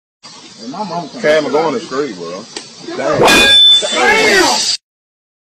Metal Slam Bouton sonore